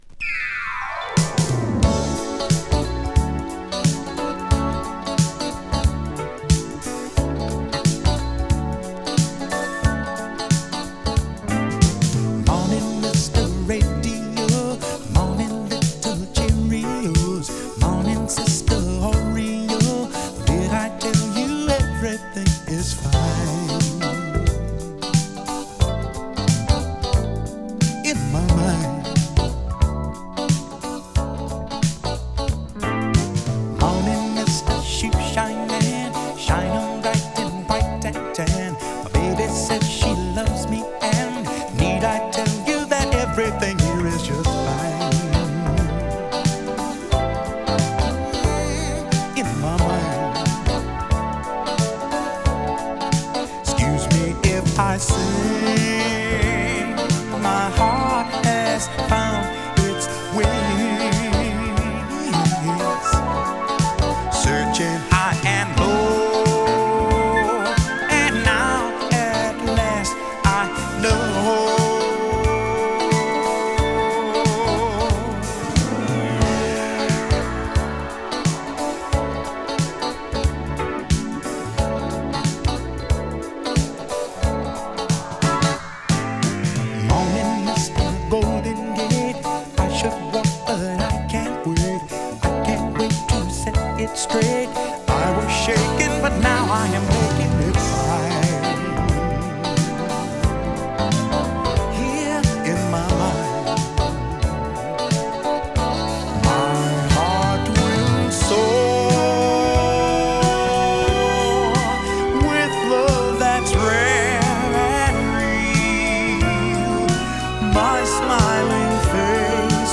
爽快グルーヴィ・ソウル